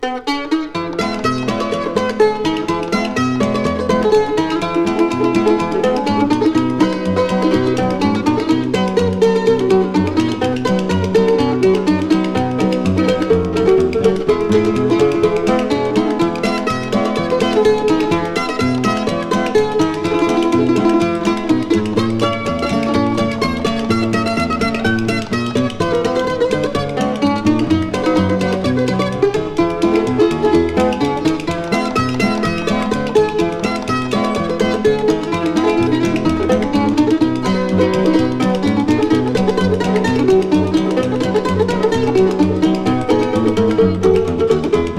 Folk, Bluegrass　USA　12inchレコード　33rpm　Mono